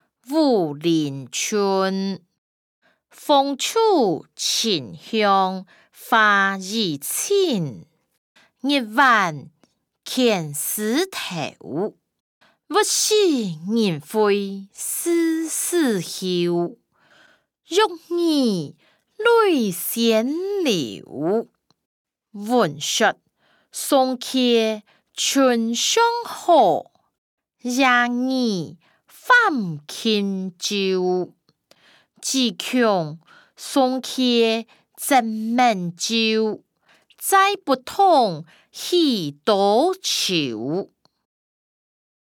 詞、曲-武陵春音檔(大埔腔)